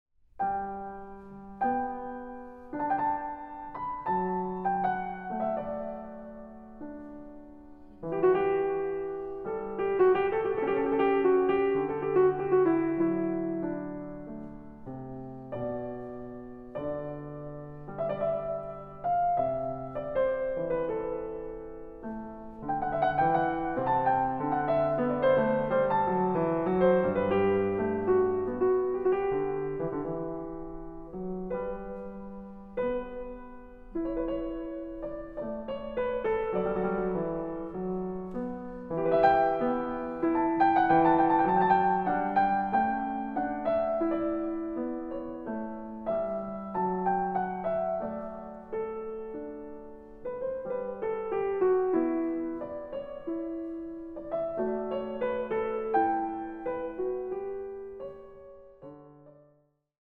Piano
Recording: Jesus-Christus-Kirche Berlin-Dahlem, 2024